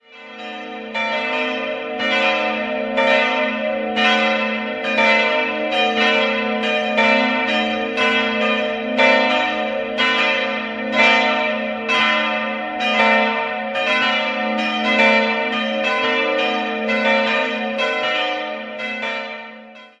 Zunächst besaß die Lutherkirche nur einen Dachreiter, der heutige Campanile wurde einige Jahre später hinzugefügt. 3-stimmiges Gloria-Geläut: b'-c''-es'' Die Glocken wurden 1960 von Rudolf Perner in Passau gegossen.